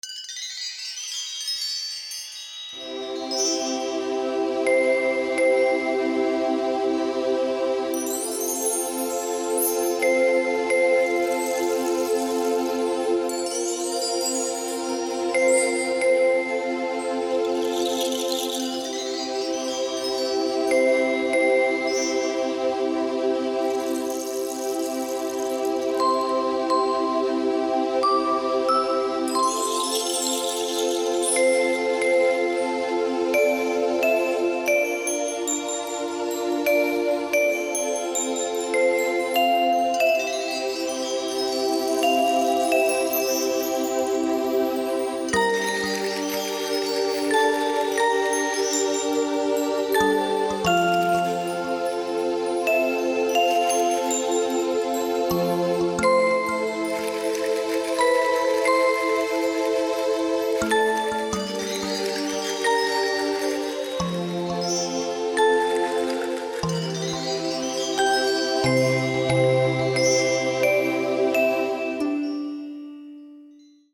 Колыбельная для детей Категория: Написание музыки